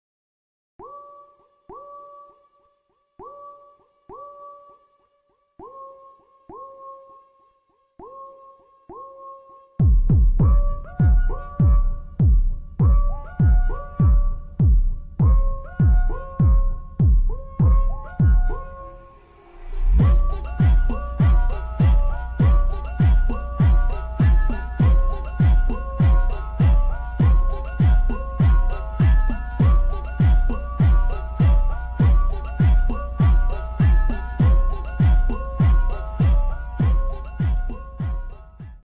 space funk